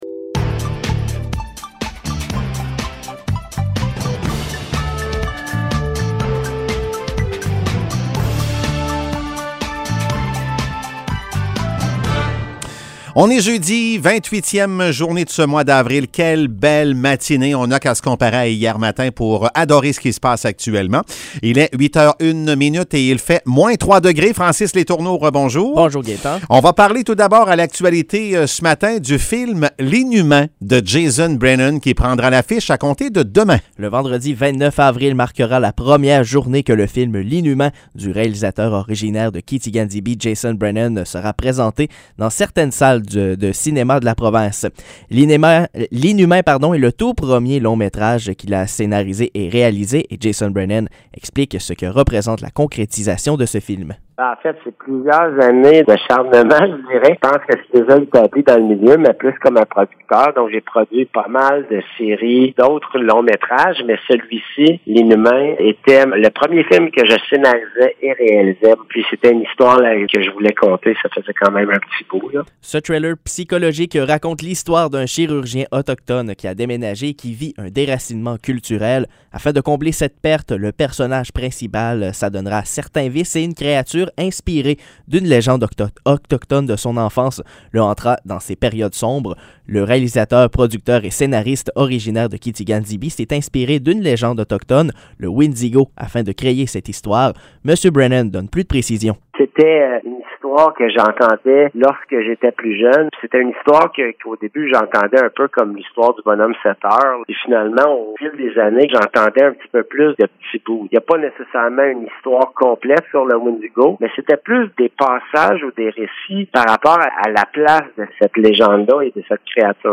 Nouvelles locales - 28 avril 2022 - 8 h